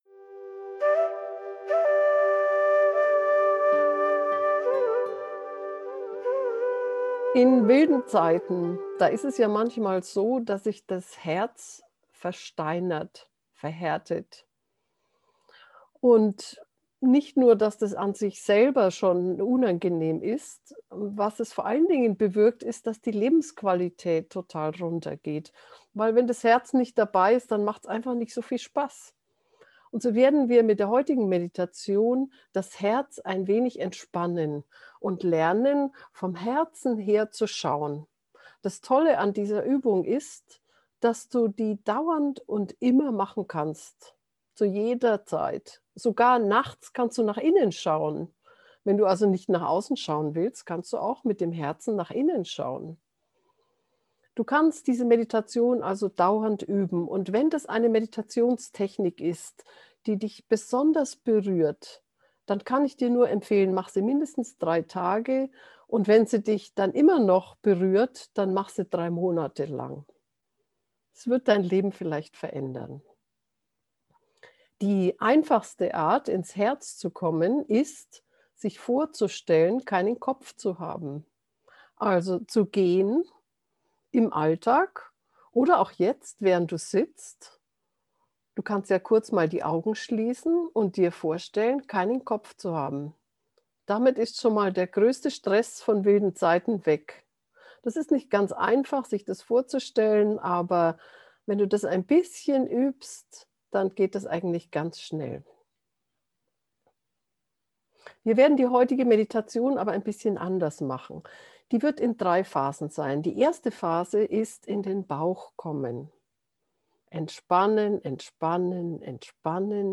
Herzmeditationen